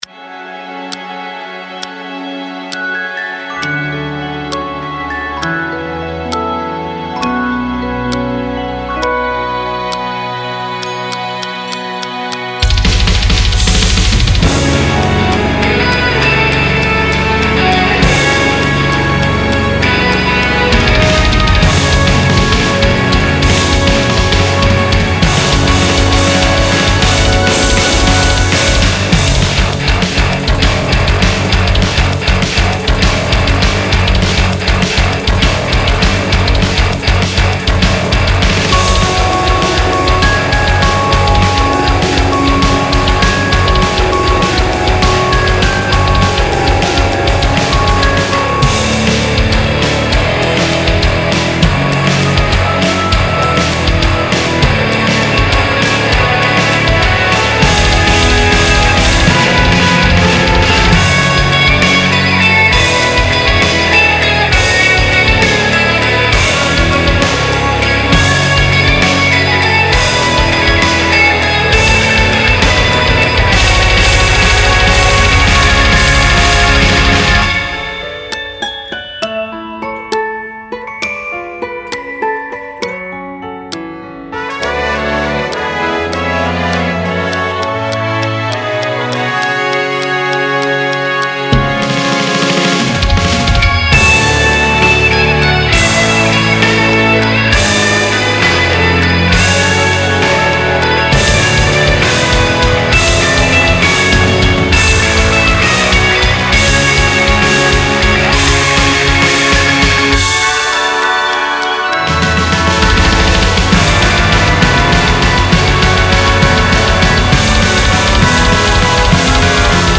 BPM60-200
Audio QualityPerfect (High Quality)
Remix